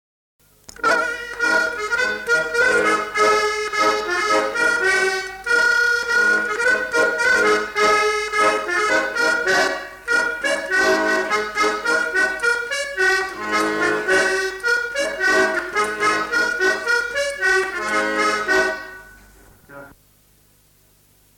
Aire culturelle : Cabardès
Lieu : Mas-Cabardès
Genre : morceau instrumental
Instrument de musique : accordéon diatonique
Danse : polka piquée
Notes consultables : Le joueur d'accordéon n'est pas identifié.